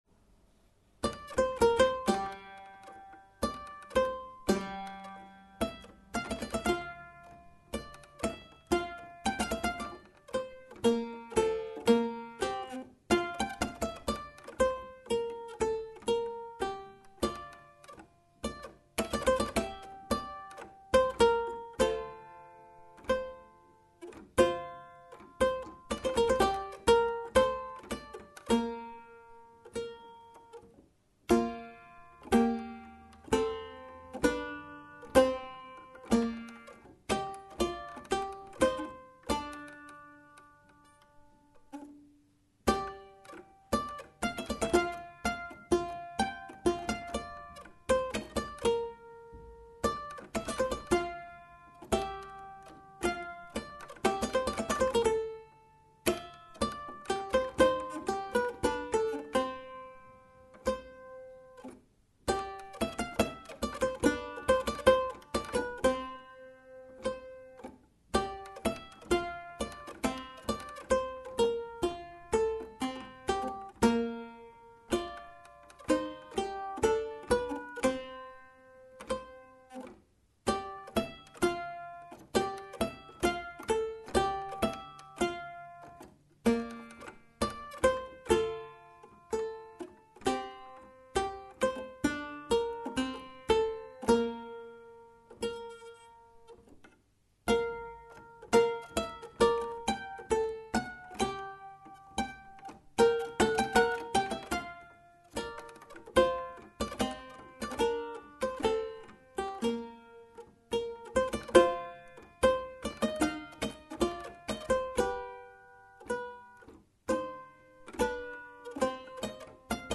Clavicordo
CLAVICORDO-ConLagreme.mp3